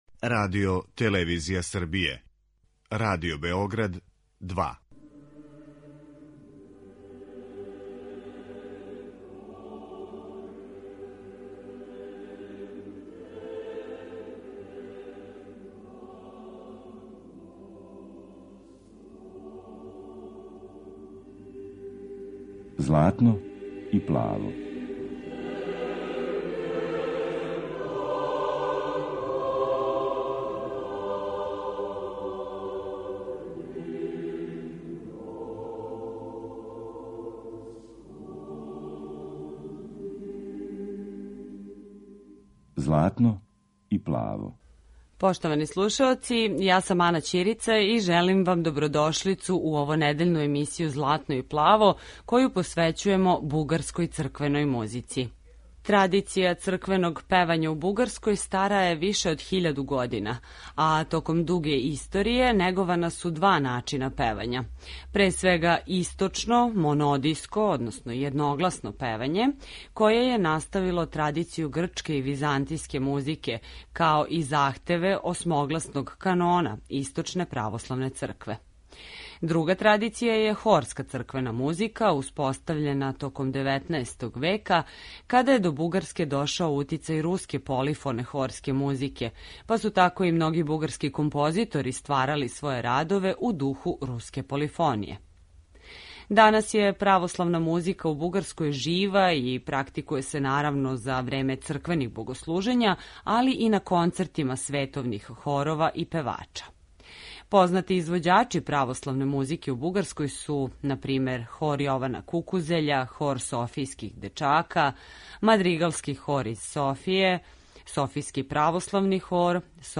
Емитујемо примере раног једногласног бугарског појања, као и дела која припадају вишегласној хорској традицији, а која потписују Добри Христов и Петар Динев.
Бугарска црквена музика